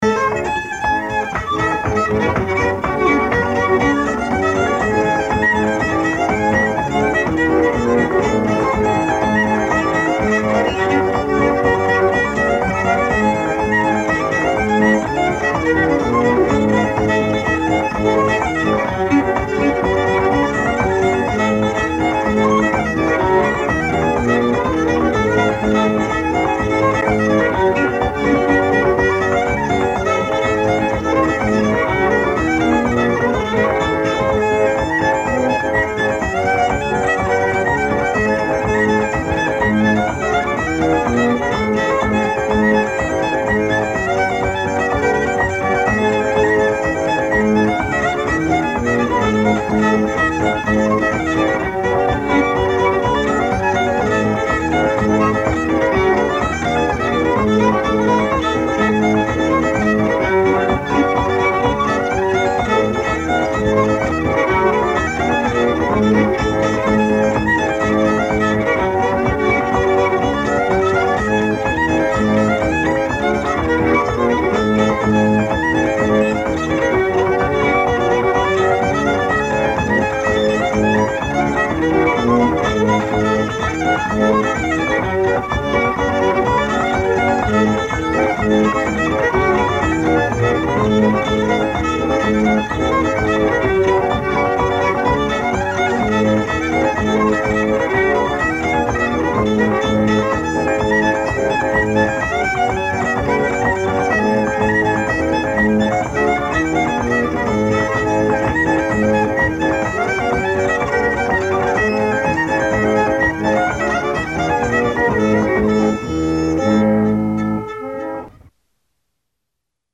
pump organ